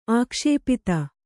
♪ ākṣēpita